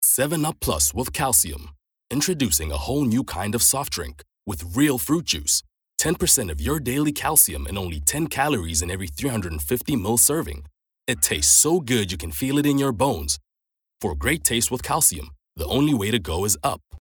authentic, authoritative, captivating, confident, dramatic, energetic, informative
Hard Sell (English US)